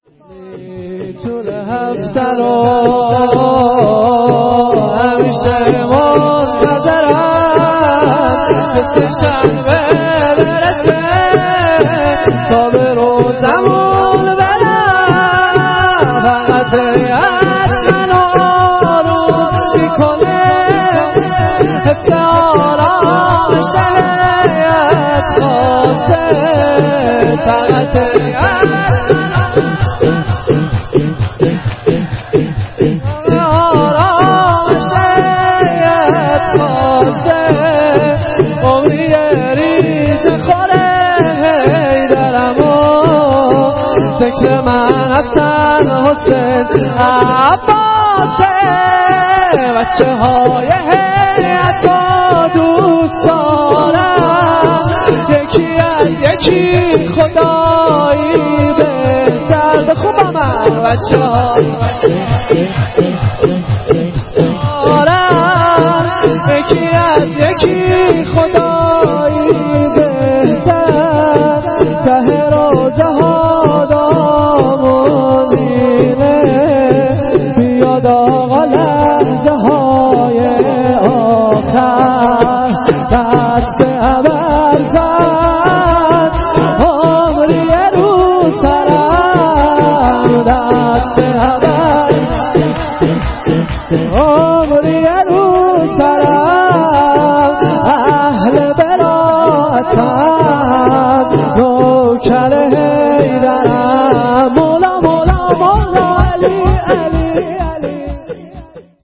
طول هفته رو همیشه منتظرم (شور....
جلسه هفتگی